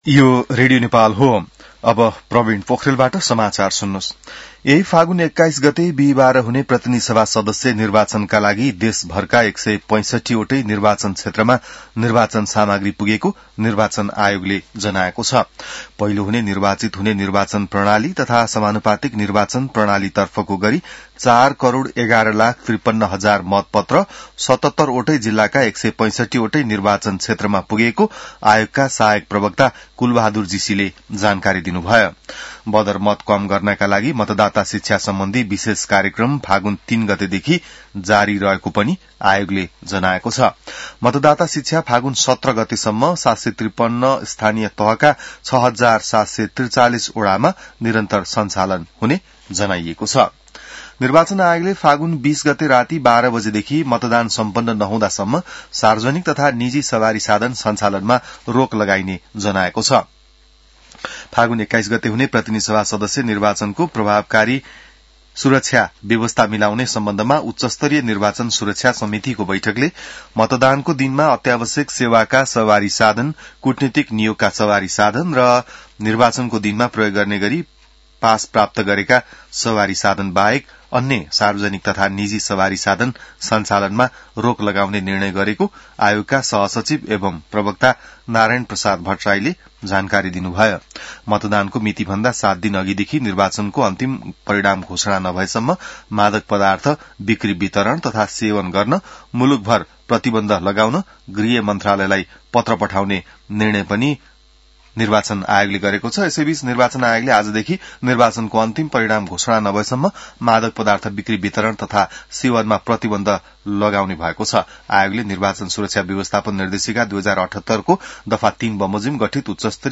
बिहान ६ बजेको नेपाली समाचार : १५ फागुन , २०८२